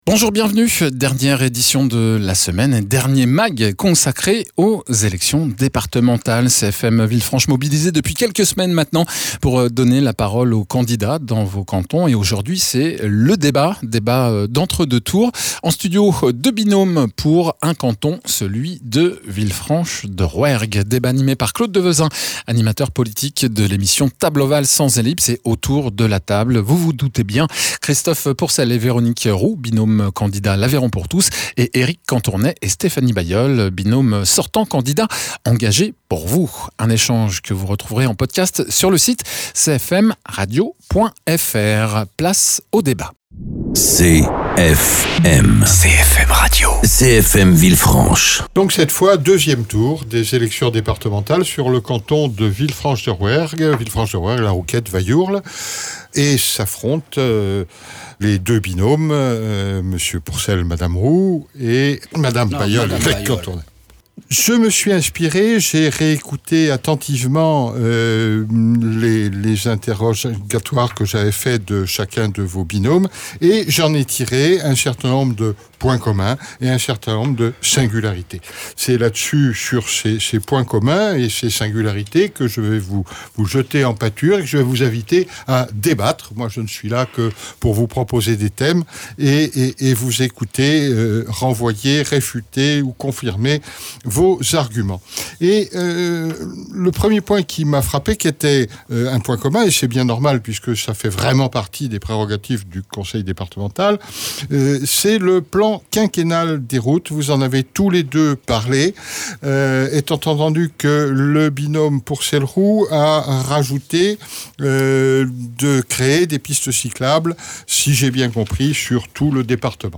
Dans la cadre des élections départementales des 20 et 27 juin, CFM Villefranche a lancé l’invitation aux candidats des 4 cantons de son bassin de diffusion. Débat d’entre deux tours pour le canton de villefranche